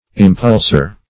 Search Result for " impulsor" : The Collaborative International Dictionary of English v.0.48: Impulsor \Im*pul"sor\, n. [L.] One who, or that which, impels; an inciter.